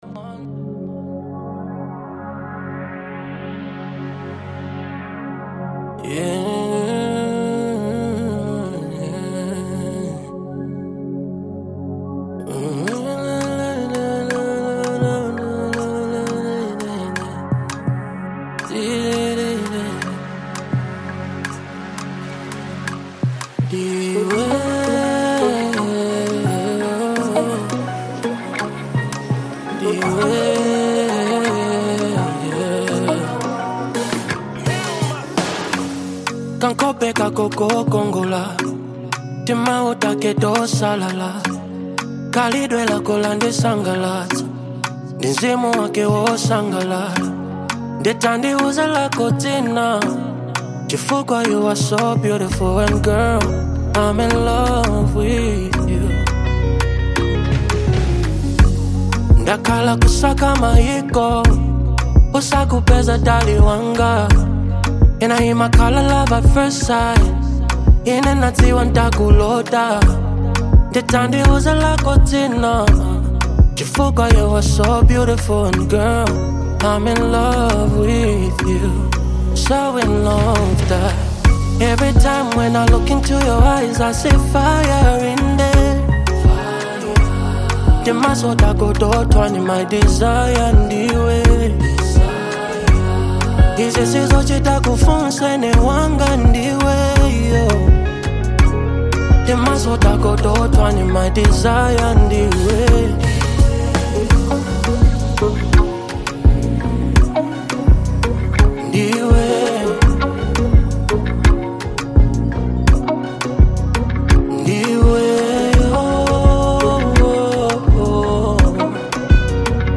Genre : RnB
In this Afro-soul infused ballad
The production is lush and detailed